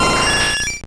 Cri de Roucarnage dans Pokémon Or et Argent.